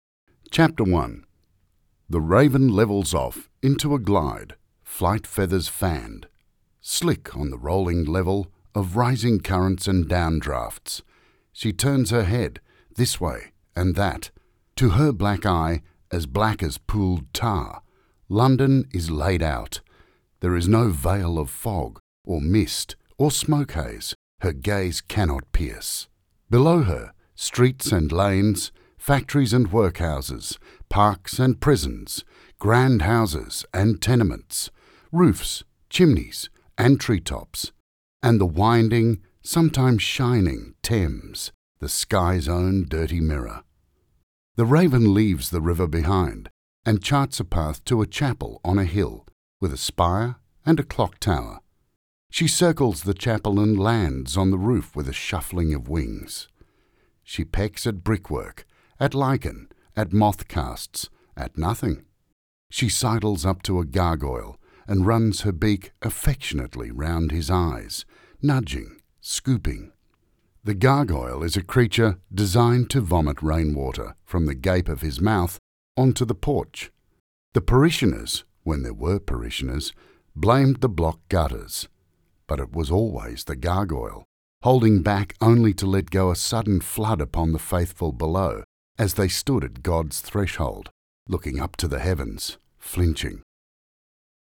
Male
English (Australian)
Older Sound (50+)
Rich, deep, resonant, versatile, authoritative. Calm, empathetic, compassionate.
Same day voiceover delivery from my professional home studio.
Audiobooks
Audiobook Narration Example